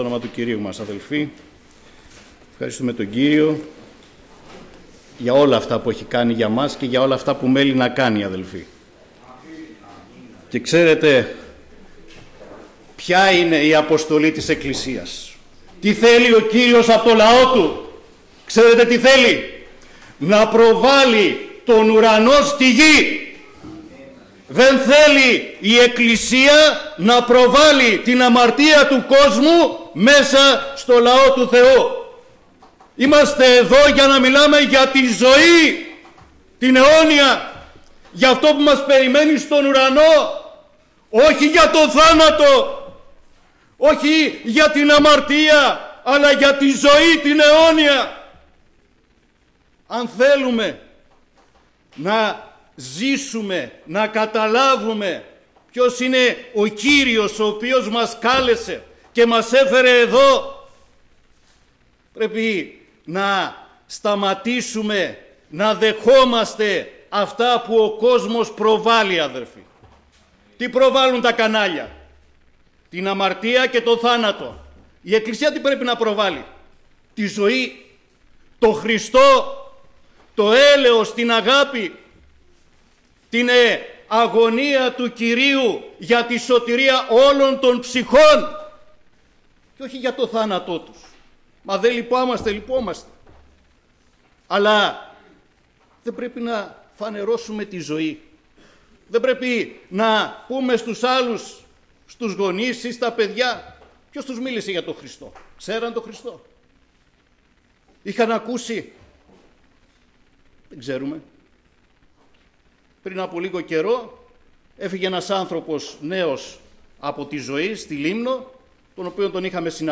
Κήρυγμα Παρασκευής